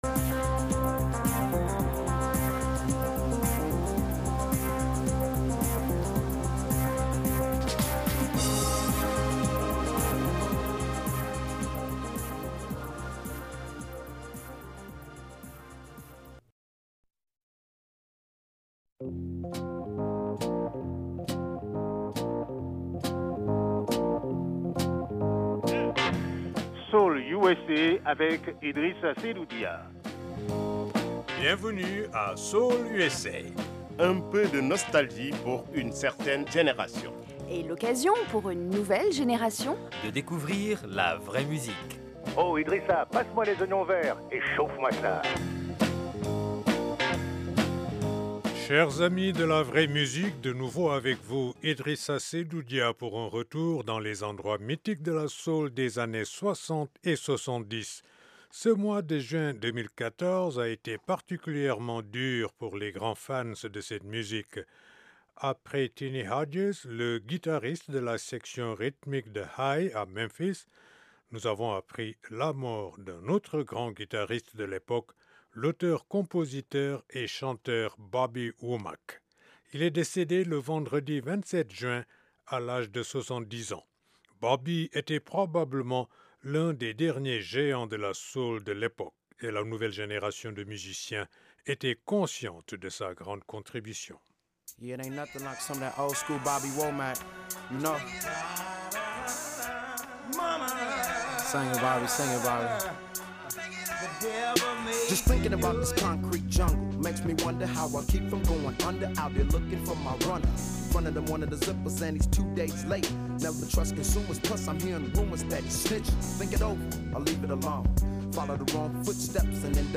Les bons vieux tubes des années 60 et 70.